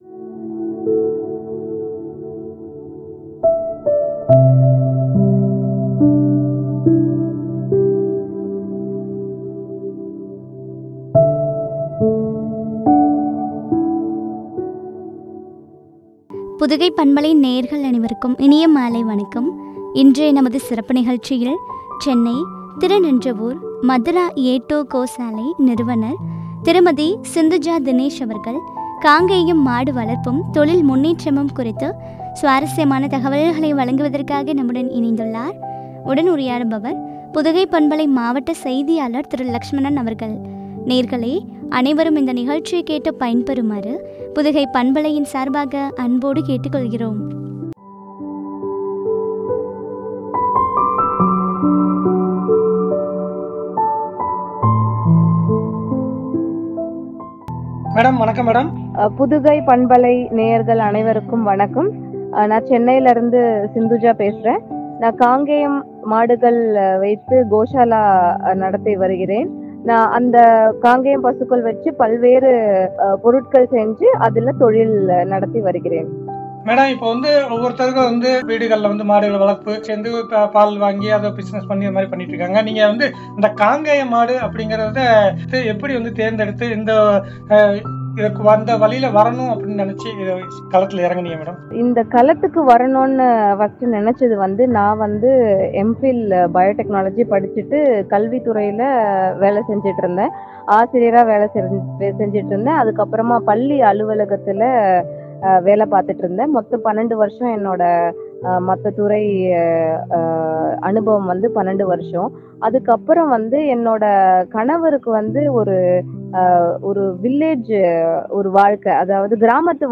தொழில் முன்னேற்றமும் ” வழங்கிய உரையாடல்.